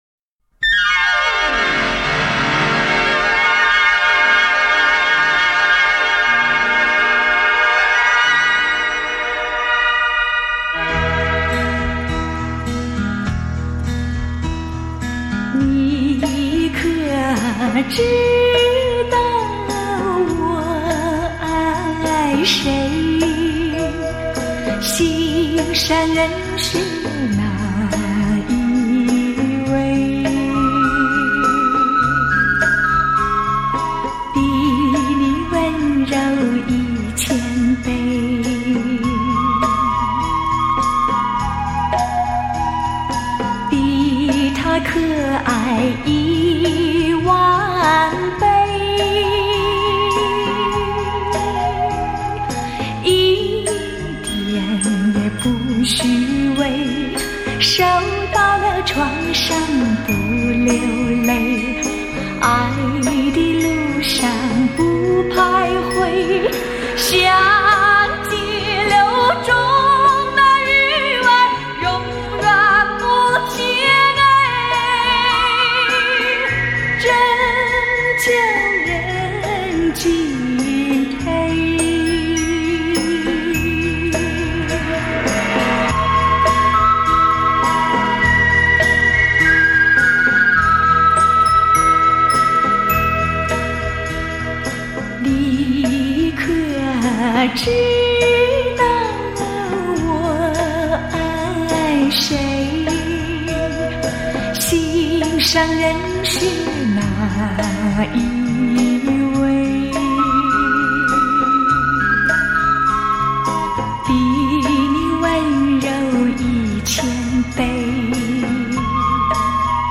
聆觅丽影君情歌华倩韵 黑胶唱片原音回放
不带粉饰的清音雅韵 纯真雅洁的朴素情怀
首度以高保真CD正式出版 原始母带经高新科技原音处理
既保留了黑胶唱片的暖和柔美 也展现了数码唱片的精确清晰
让那甜蜜柔美的声音再度飘进你的心窝 萦牵你的心弦